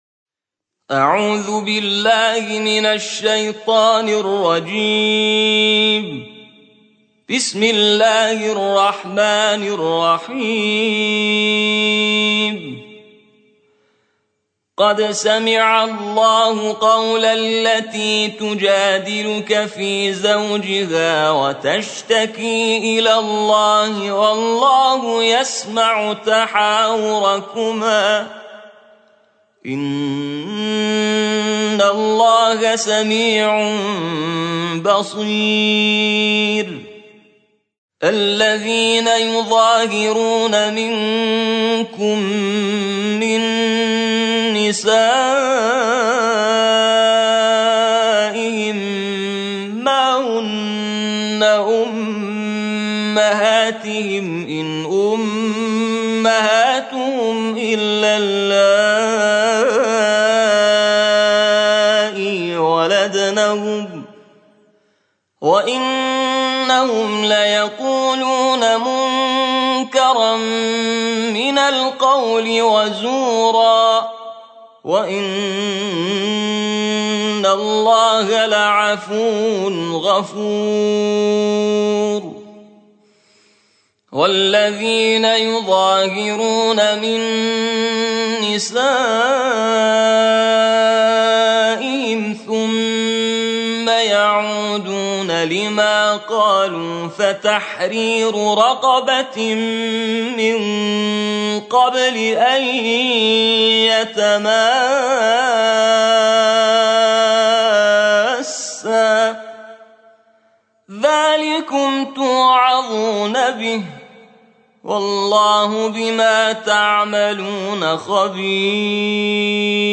ترتیل جزء 28 قرآن